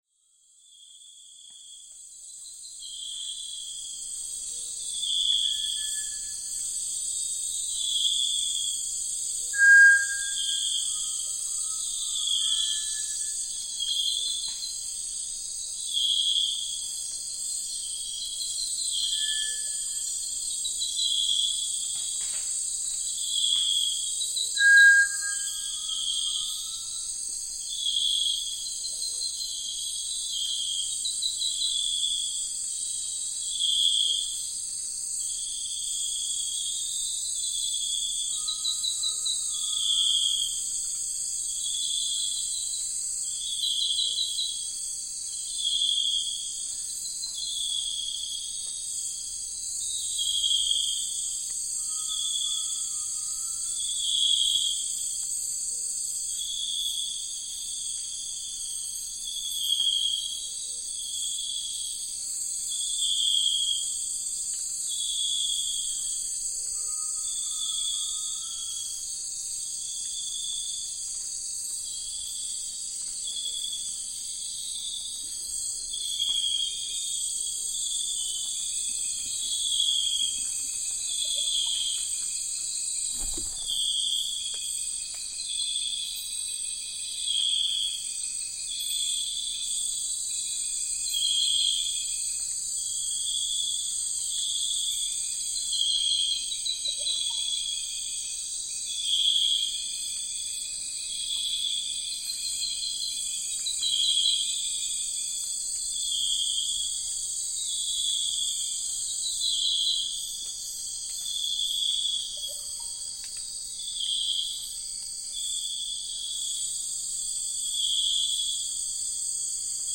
Amazon biosphere - dawn chorus
At dawn in the heart of the Amazon Biosphere, Colombia, the world emerges from its slumber in a harmonious symphony of life. The dense, emerald-green rainforest envelops you, and the morning stillness gives way to a vibrant medley of birdcalls, insect chatter, and distant howler monkeys marking their territory. This timeless soundscape reflects the pulse of one of the most biodiverse regions on Earth, a sanctuary teeming with life awakening under the golden hues of a new day.